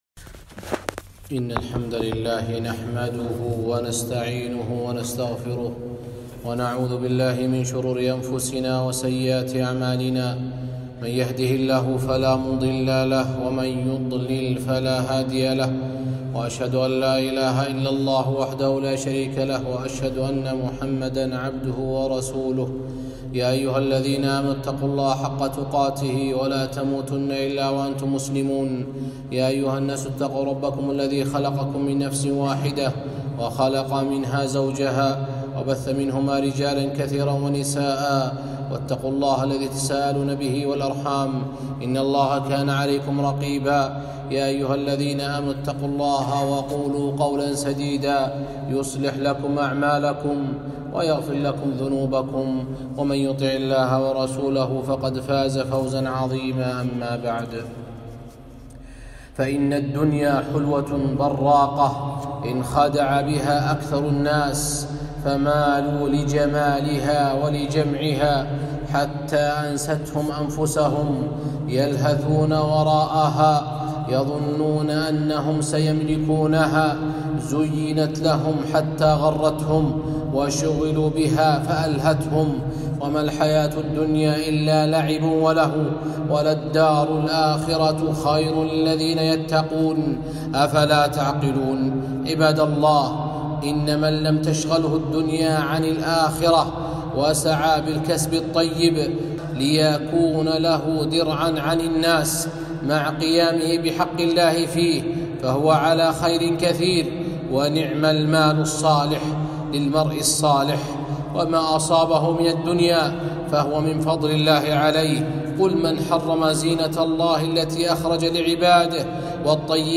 خطبة - الدنيا حلوة